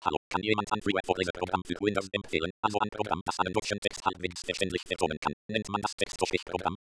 • ▲ ▼ Hier ist eine Kostprobe mit hochgedrehter Geschwindigkeit...
Gespeaker.wav